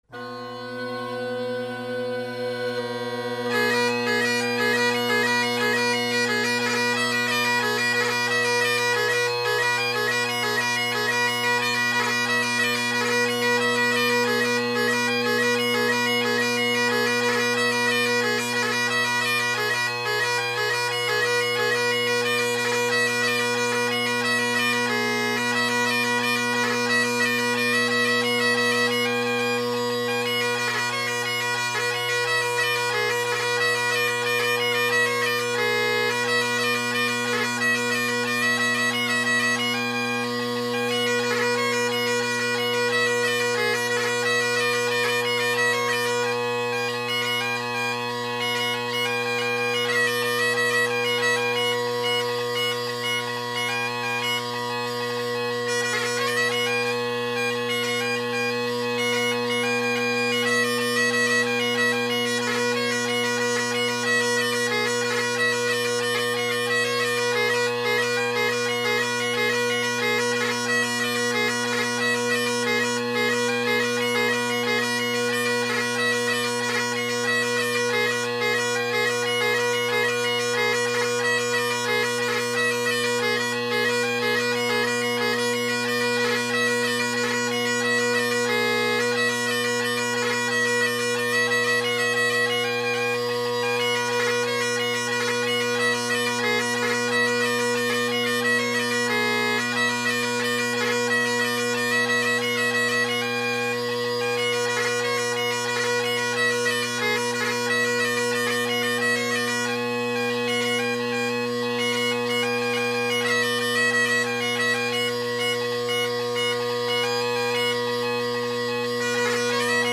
Drone Sounds of the GHB, Great Highland Bagpipe Solo
I hope you enjoy listening in on my jams: